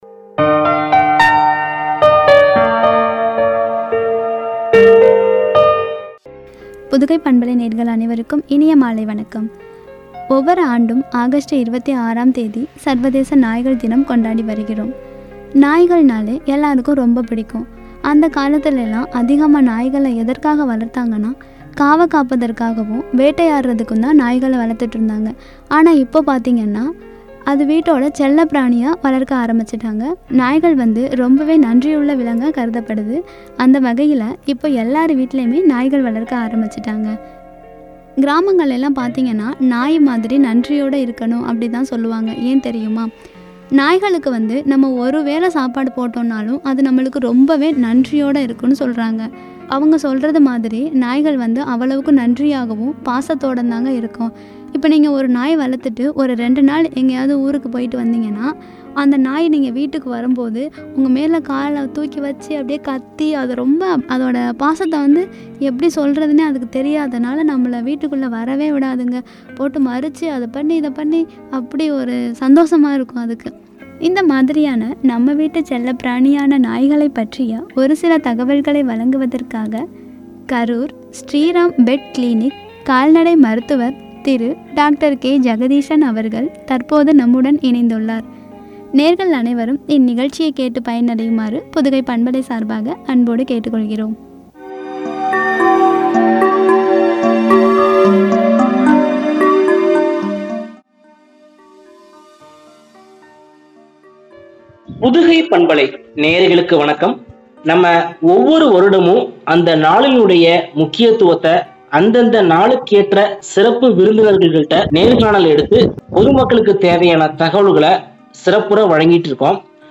“செல்லப்பிராணி நாய்” எனும் தலைப்பில் வழங்கிய உரையாடல்.